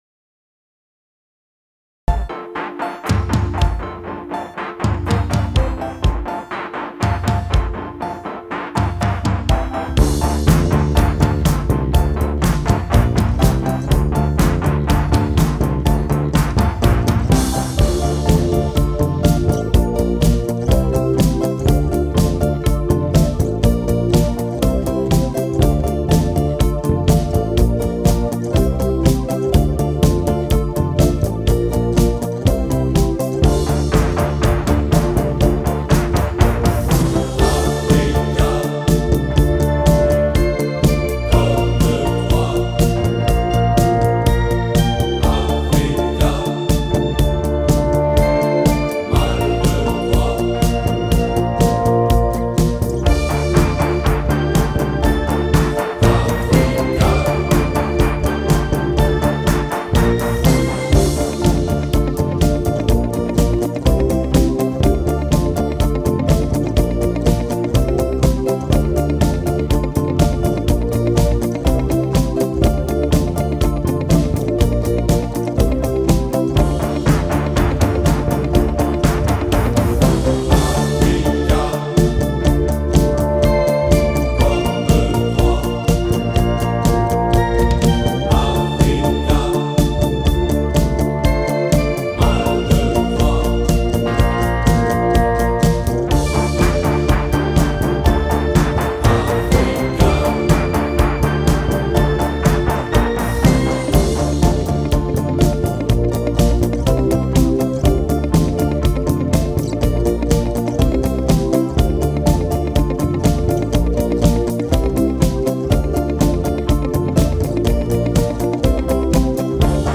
Télécharger Africa (instrumental).mp3
africa-instrumental.mp3